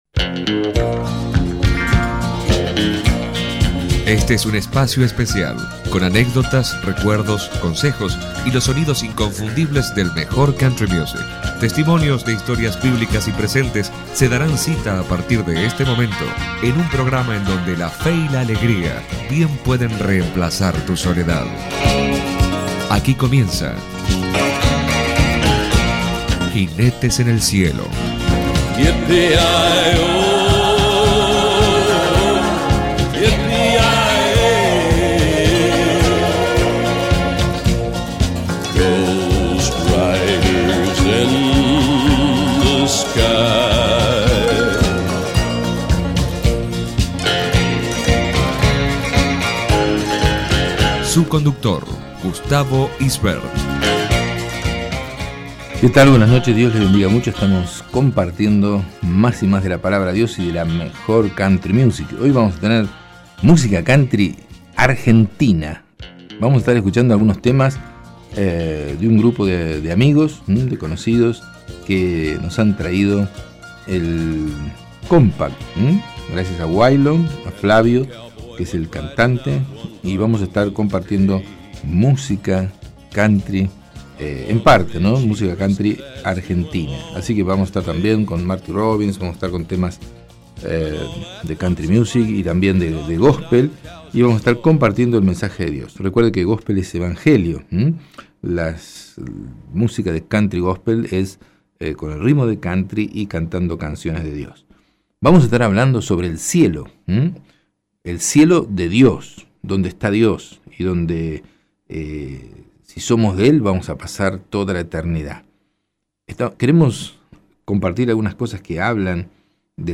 Programa de Radio Jinetes en el Cielo para escuchar o regalar donde una sólida palabra de Dios nos revela cómo las personas que ponen su fe en Cristo puede tener acceso a una recompensa eterna en el cielo.